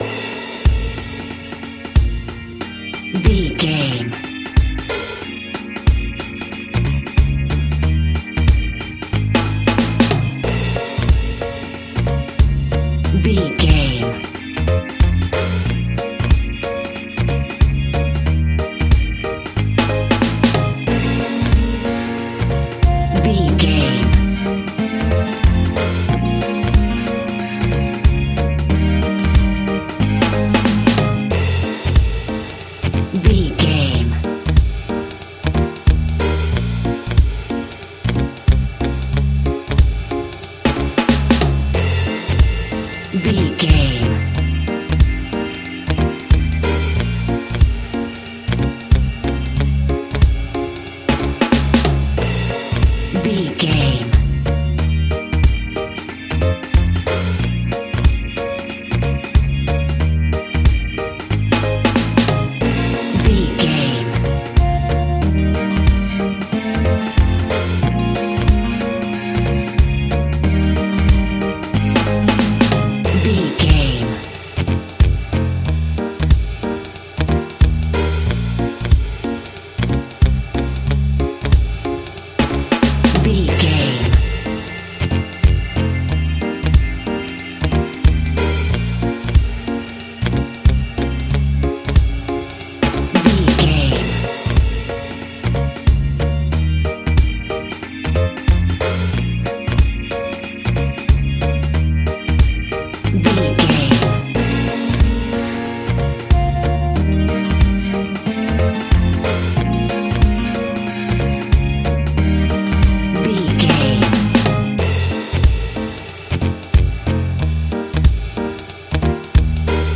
A fast and speedy piece of Reggae music, uptempo and upbeat!
Uplifting
Aeolian/Minor
B♭
laid back
off beat
drums
skank guitar
hammond organ
percussion
horns